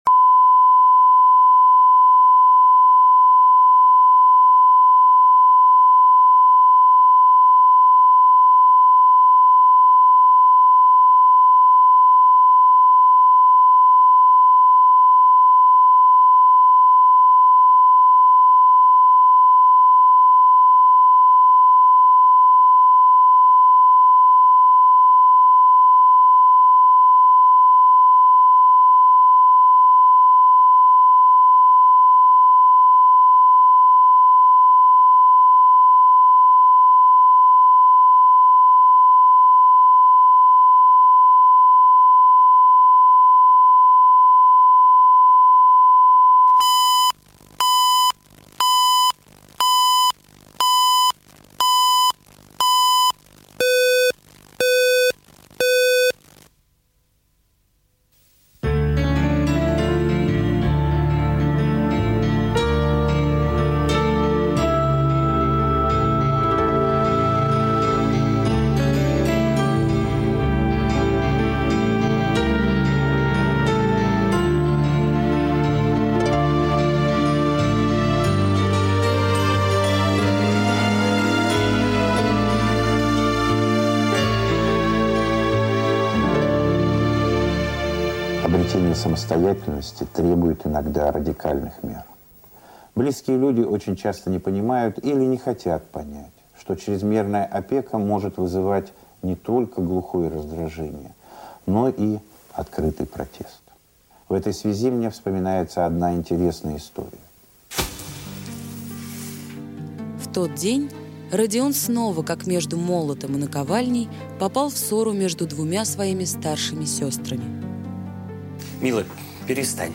Аудиокнига Младший братик | Библиотека аудиокниг
Прослушать и бесплатно скачать фрагмент аудиокниги